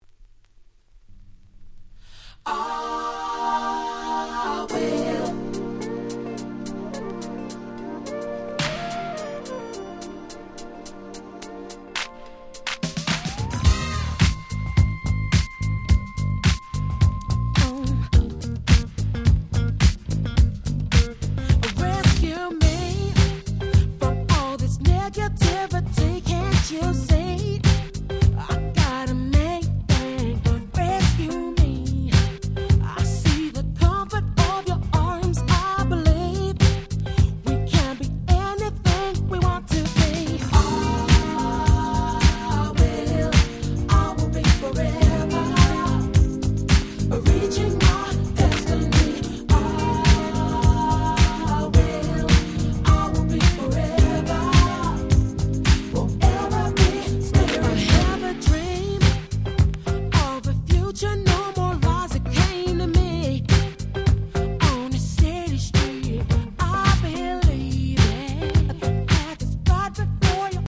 Nice UK R&B EP !!
Acid Jazz アシッドジャズ